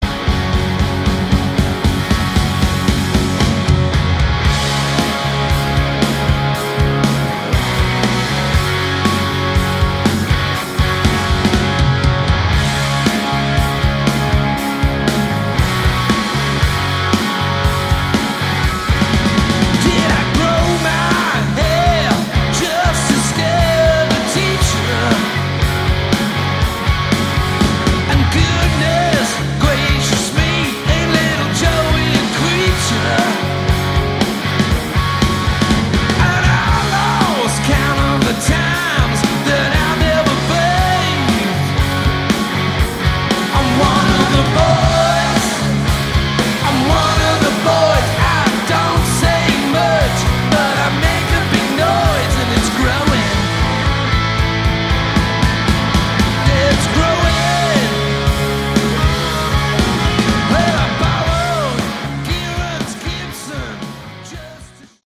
Category: Hard Rock
vocals, guitar, keyboards
drums
bass